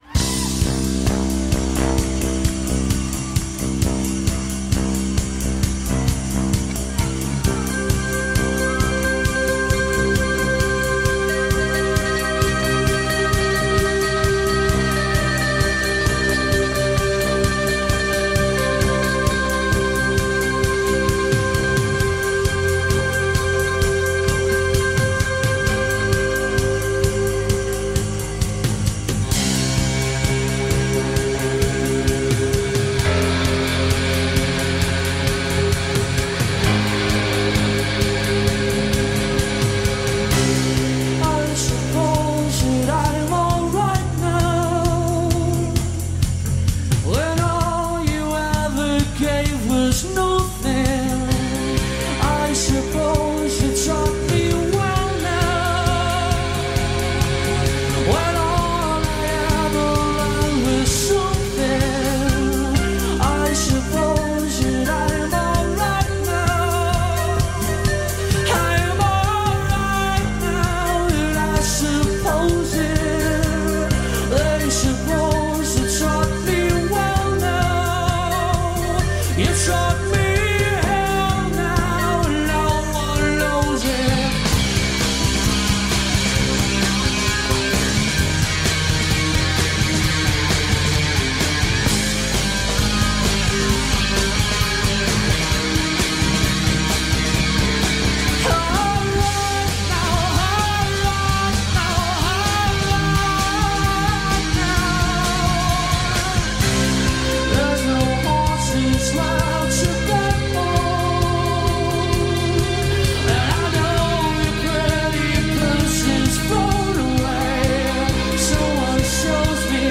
high-register, ethereal vocals